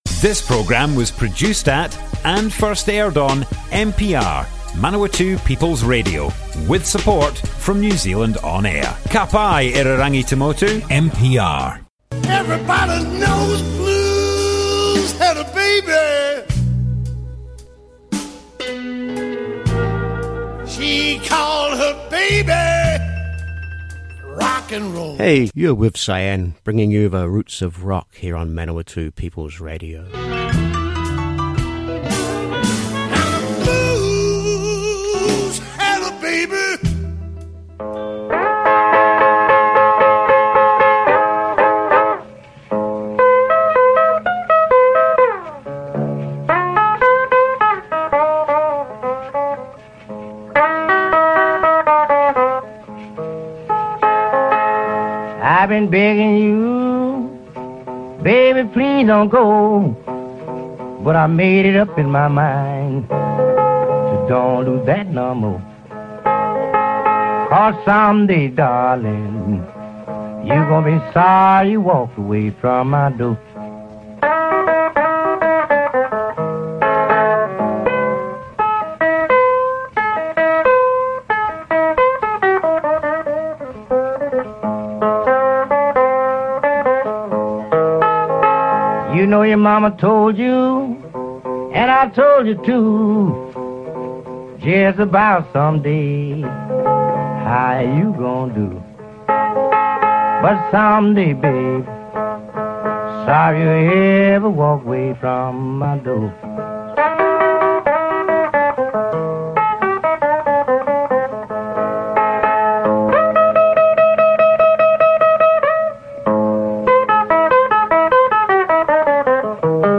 Blues had a baby and its name was Rock 'n' Roll!